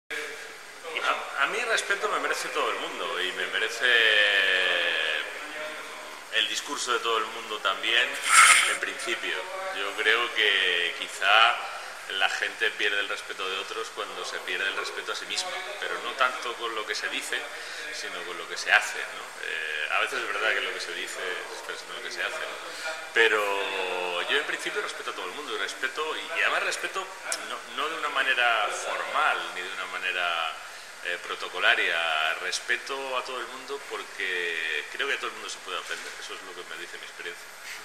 Entrevista al escritor y guionista Lorenzo Silva.
Puedes escuchar el audio de la respuesta aquí: